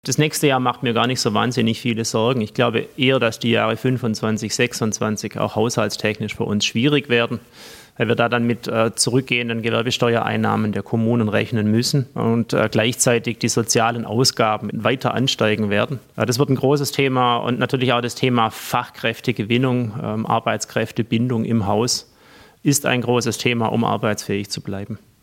Mario Glaser (parteilos), Landrat Kreis Biberach